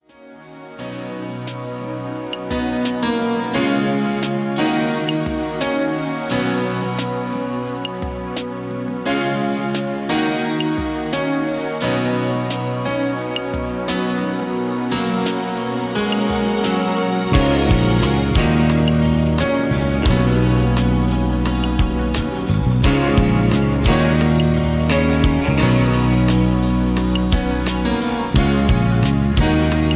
(Key-F#) Karaoke MP3 Backing Tracks
Just Plain & Simply "GREAT MUSIC" (No Lyrics).